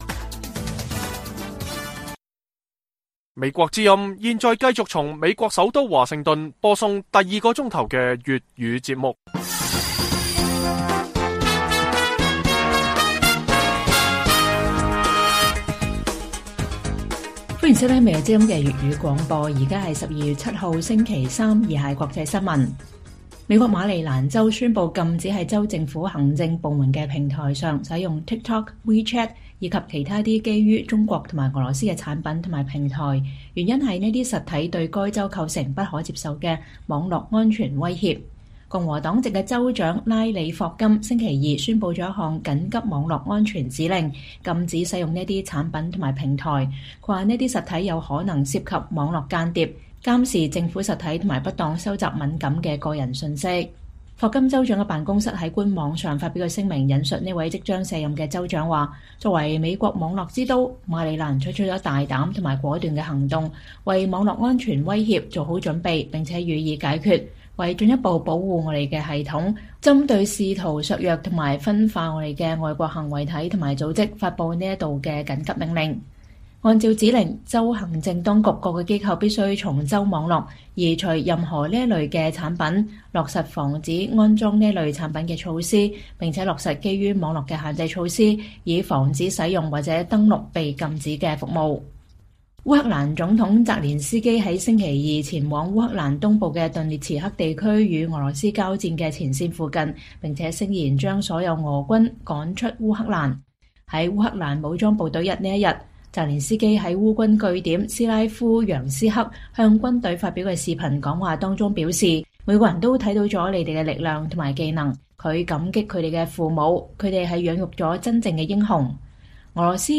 粵語新聞 晚上10-11點: 美國馬里蘭州宣布州政府禁用TikTok與WeChat